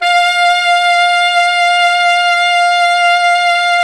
Index of /90_sSampleCDs/Roland LCDP07 Super Sax/SAX_Sax Ensemble/SAX_Sax Sect Ens
SAX 2 ALTO0A.wav